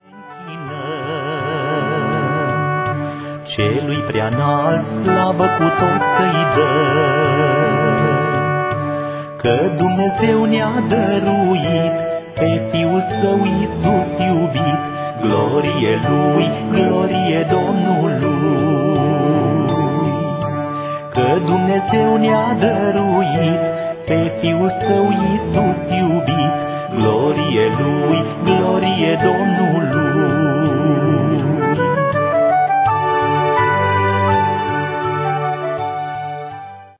colinde